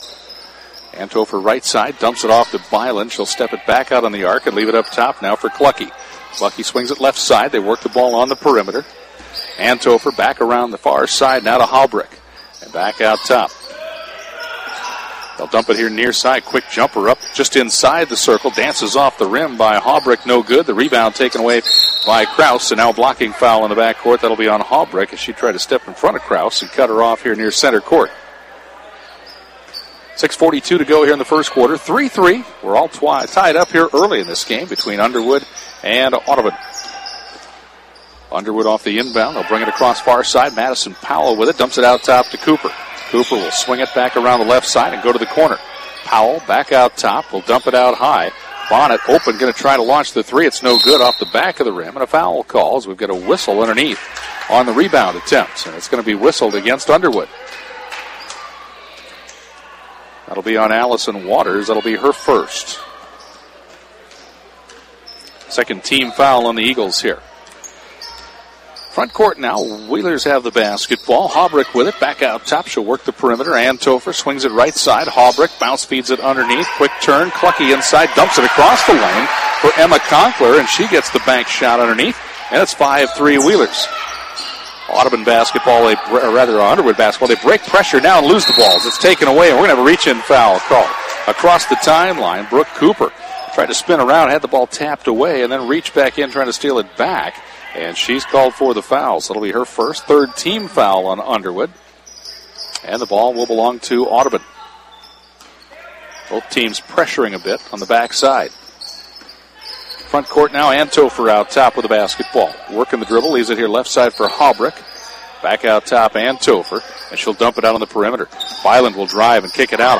have the call of the Varsity Girls and Boys games played at Audubon High School.